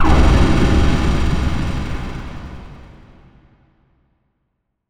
Impact 21.wav